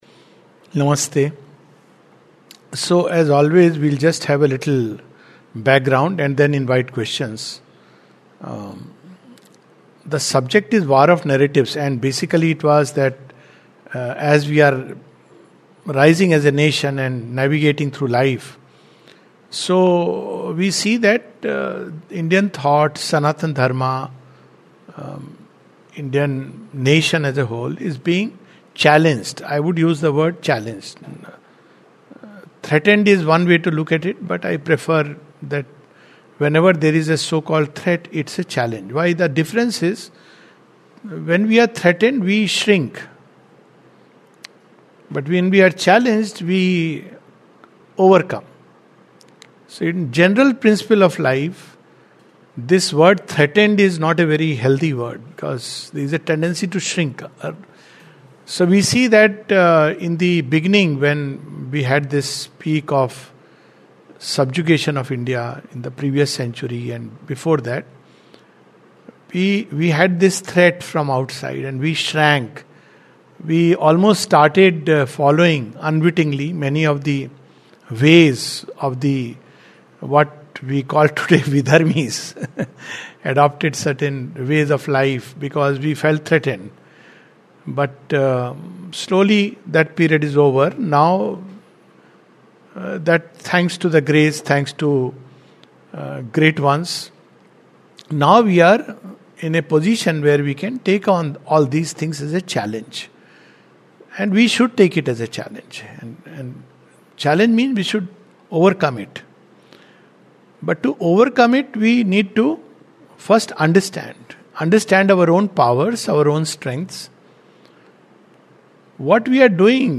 This webinar is focused upon the different Narratives and the way we could possibly meet them.